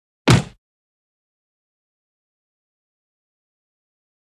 赤手空拳击中肉体12-YS070524.wav
通用动作/01人物/03武术动作类/空拳打斗/赤手空拳击中肉体12-YS070524.wav
• 声道 立體聲 (2ch)